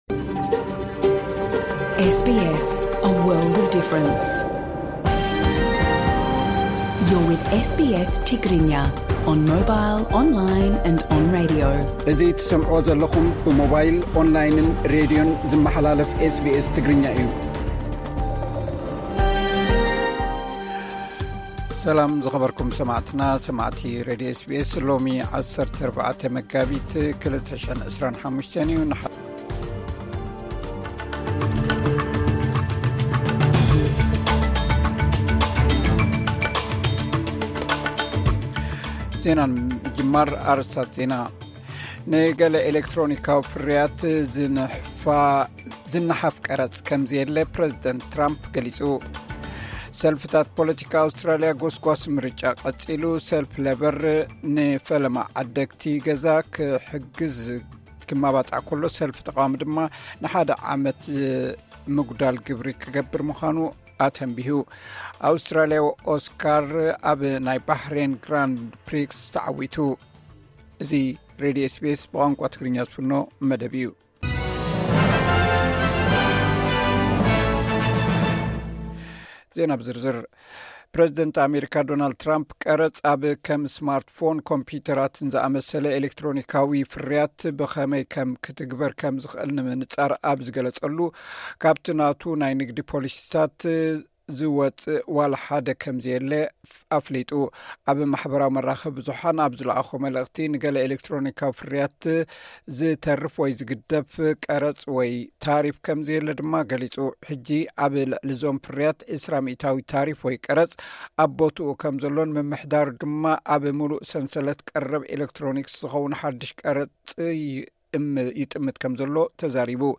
ዕለታዊ ዜና ኤስ ቢ ኤስ ትግርኛ (14 መጋቢት 2025)